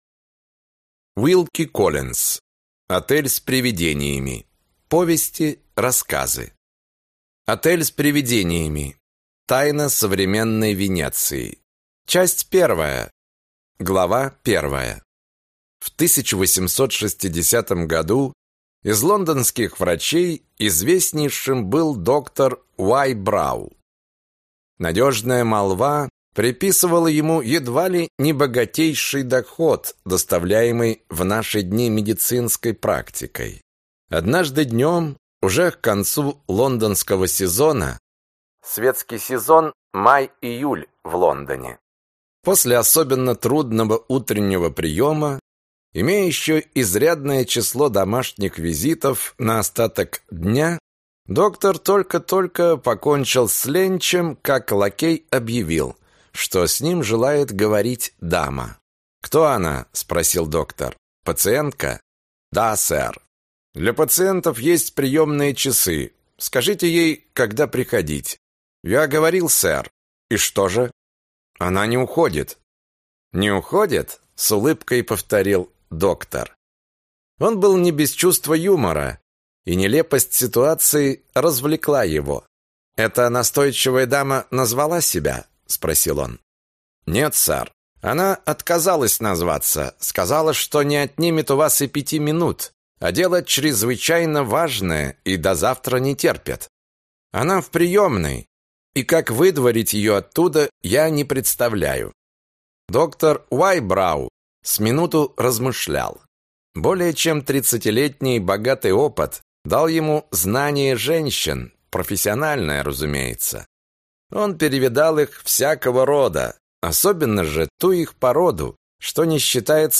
Аудиокнига Отель с привидениями | Библиотека аудиокниг